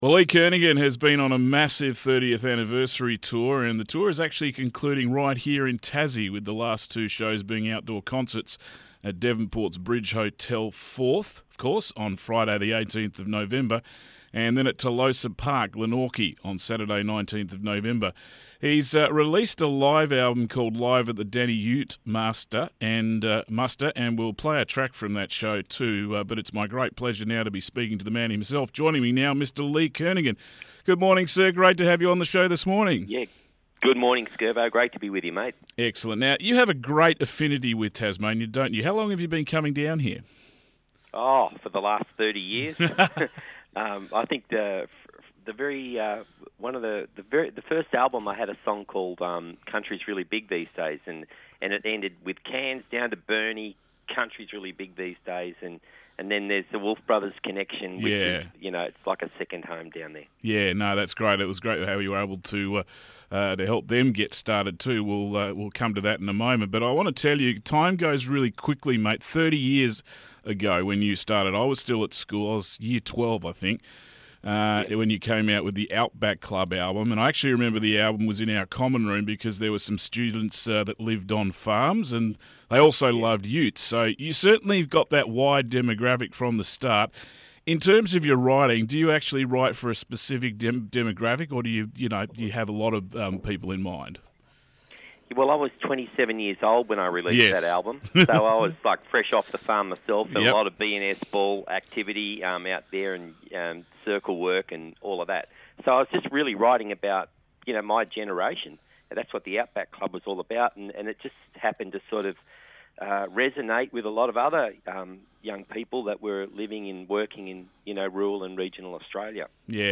INTERVIEW WITH LEE KERNAGHAN